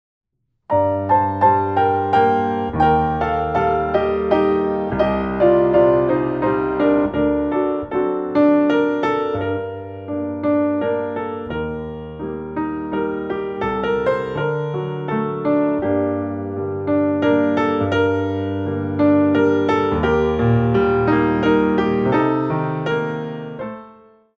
4 bar intro 3/4
64 bars